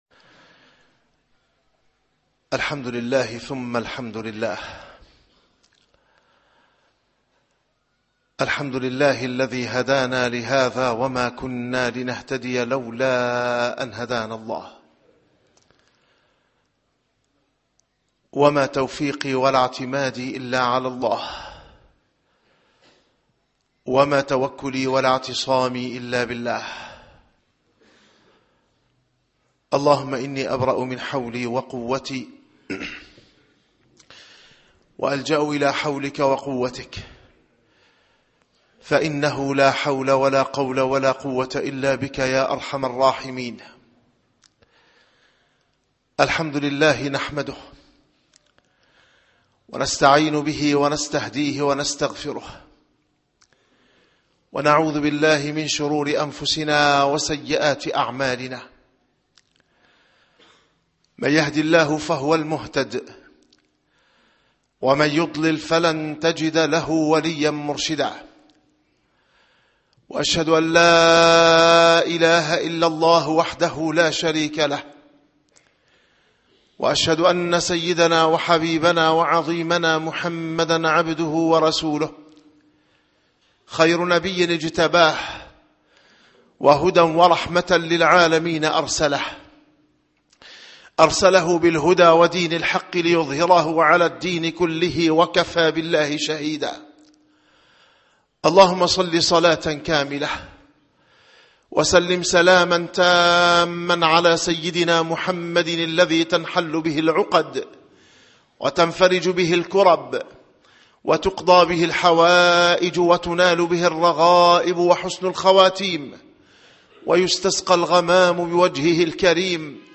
- الخطب - رسول الله صلى الله عليه وسلم أشرف الوسائط إلى الله ومفهوم ذلك عقيدة